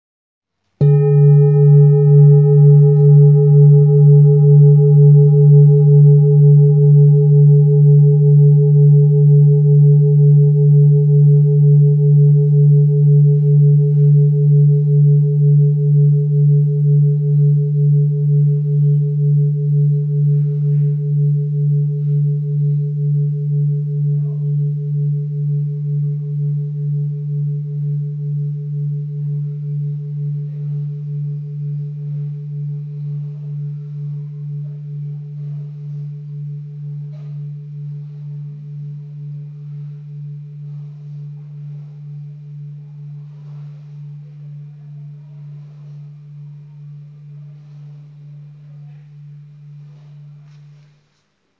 Sacra Chakra High Quality Singing Bowl Jambati, with Green Tara Carving , A bowl used for meditation and healing, producing a soothing sound that promotes relaxation and mindfulness
Singing Bowl Ching Lu Kyogaku
Material 7 Metal Bronze